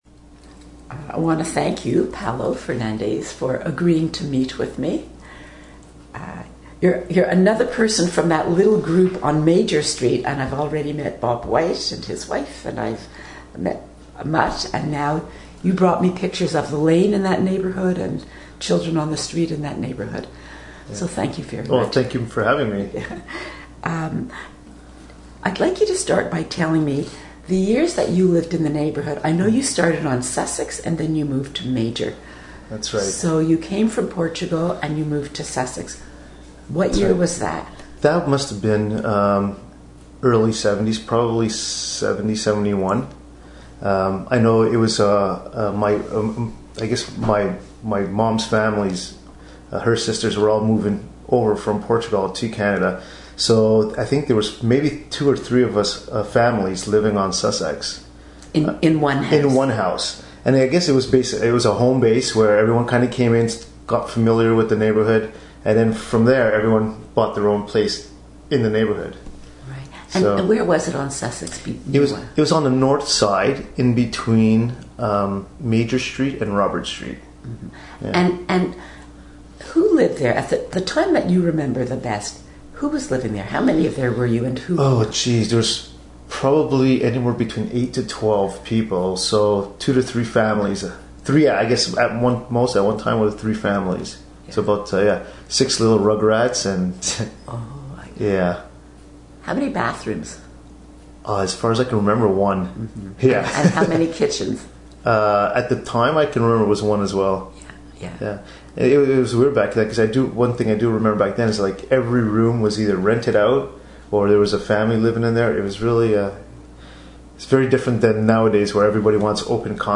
Harbord Village Oral History Project 2013